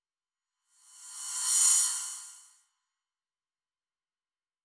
MB Reverse Crash (2).wav